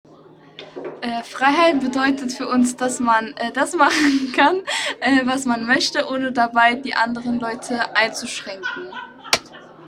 MS Wissenschaft @ Diverse Häfen
Standort war das Wechselnde Häfen in Deutschland.